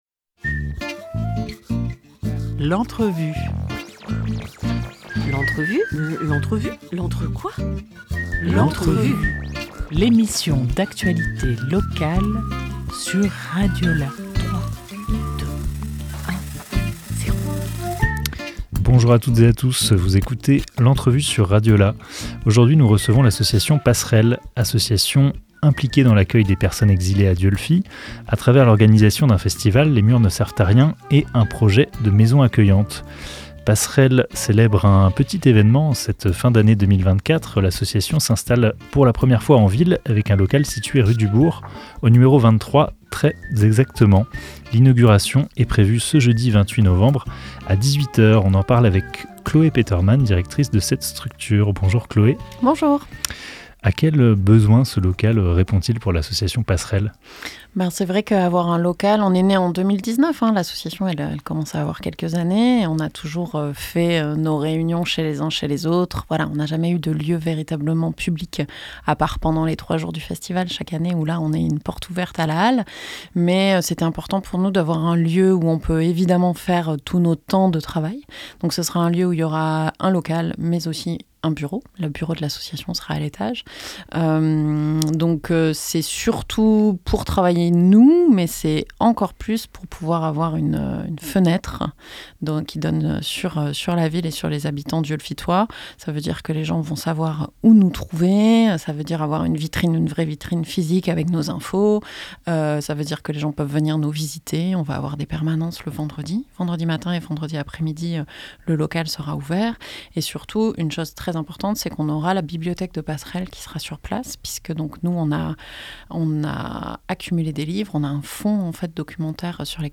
26 novembre 2024 10:58 | Interview